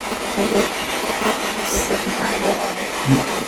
Spirit Box Clip 6 Villisca Axe Murder House Spirit Box Clip 6 As we started walking upstairs during an SB11spirit box session, the clear voice of a young female came through.